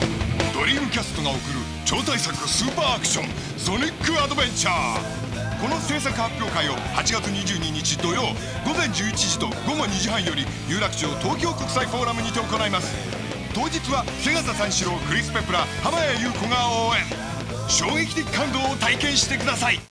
Radio version
For this presentation, we wanted to reach as many users as possible, so we participated in a radio CF for the first time.